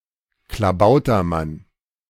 A Klabautermann (German: [klaˈbaʊtɐˌman]